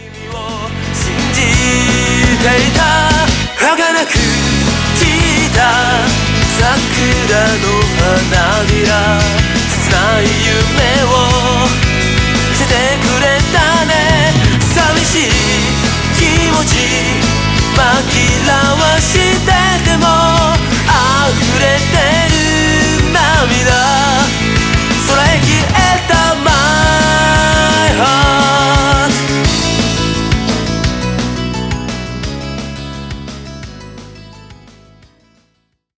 ポップな楽曲をメインに集めた新シリーズ登場！
V系ロック